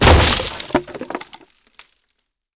劈劈柴－YS070511.wav
通用动作/01人物/06工作生产/劈劈柴－YS070511.wav
• 声道 立體聲 (2ch)